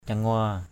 /ca-ŋʊa/ (d.) nia = van. urang jiong di mik saong wa, drei maaom cangua kaoh gai pataok ur/ _j`U d} m|K _s” w%, d] m_a> cz&% _k<H =g p_t<K người...